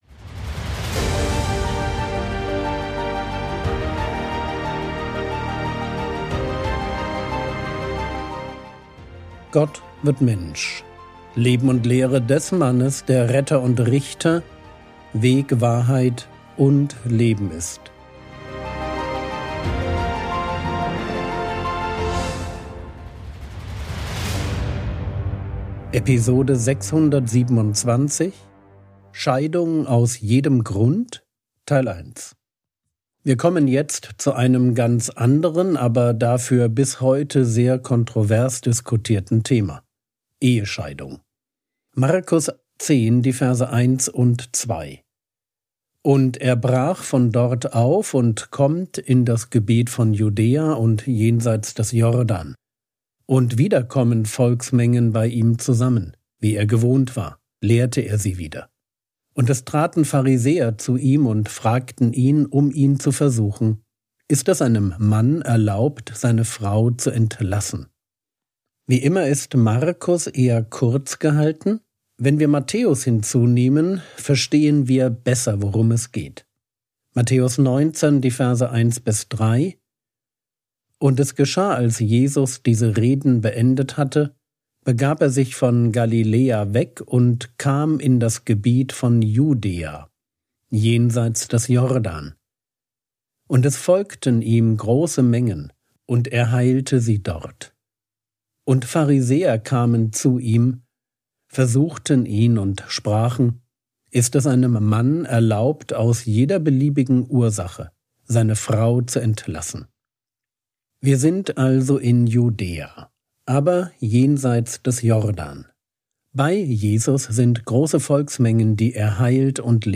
Episode 627 | Jesu Leben und Lehre ~ Frogwords Mini-Predigt Podcast